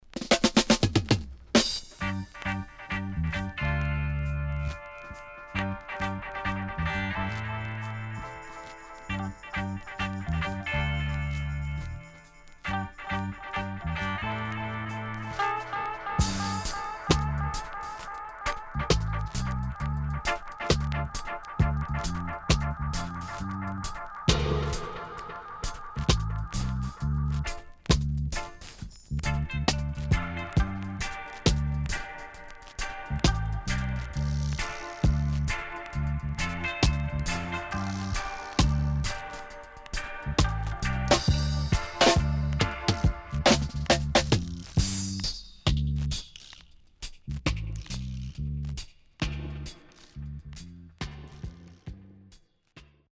Rare.Great Deep Roots & Dubwise.W-Side Good
SIDE A:所々ジリノイズがあり、少しプチノイズ入ります。